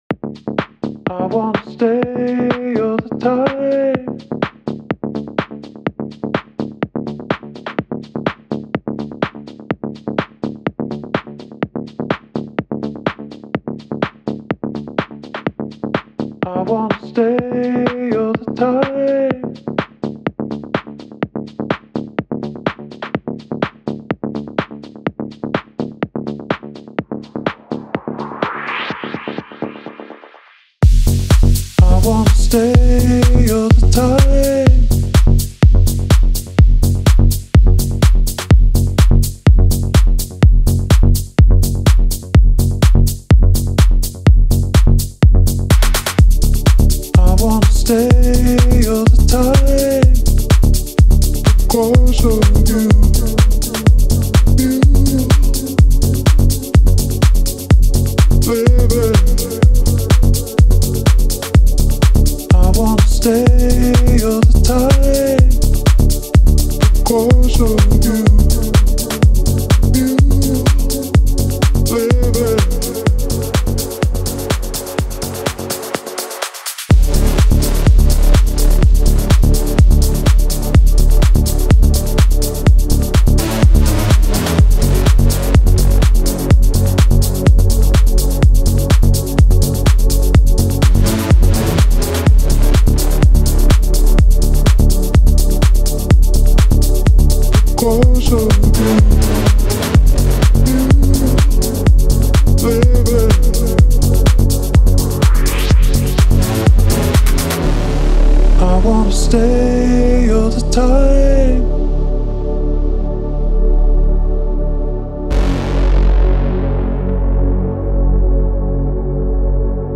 اهنگ خارجی مخصوص ورزش پرانرژی